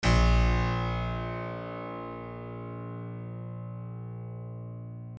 piano-sounds-dev
HardPiano